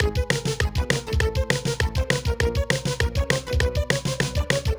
clock-timer.wav